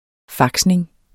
Udtale [ ˈfɑgsneŋ ]